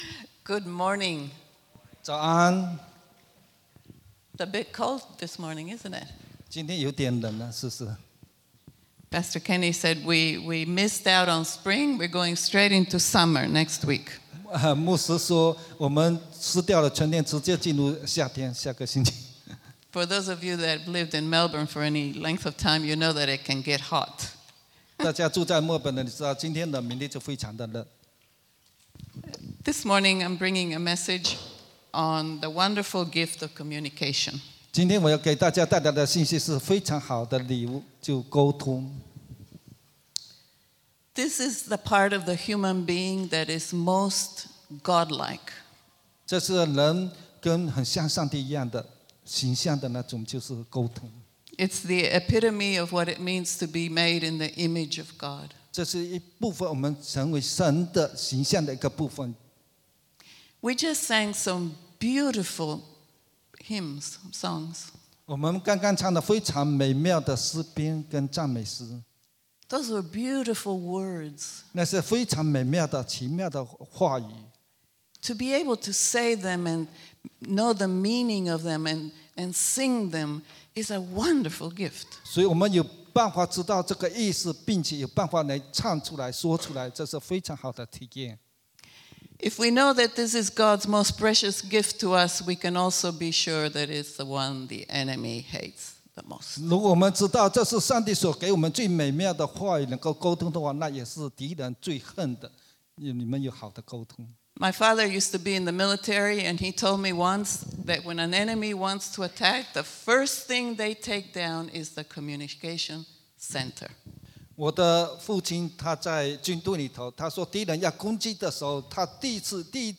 English Sermons | Casey Life International Church (CLIC)
Bilingual Worship Service - 27th Nov 2022